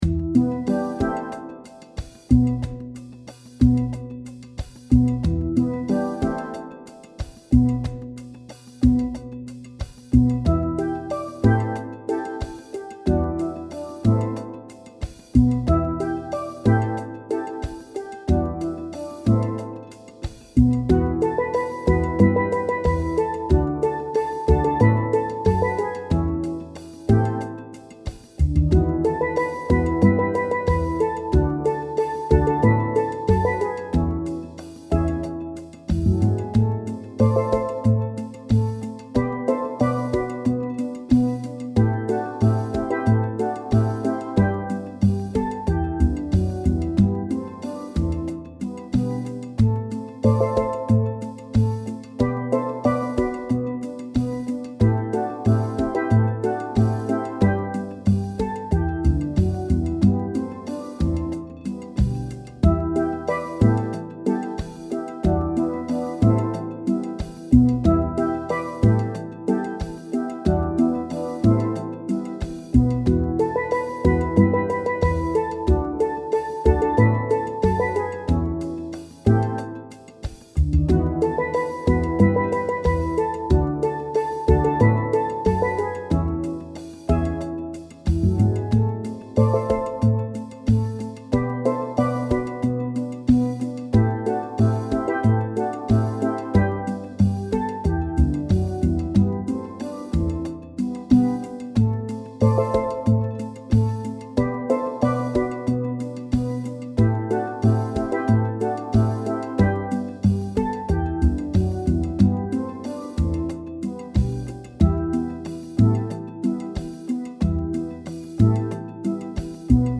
Steel Band Sound Files
These mp3's are up to tempo.